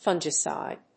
音節fun・gi・cide 発音記号・読み方
/fˈʌndʒɪsὰɪd(米国英語)/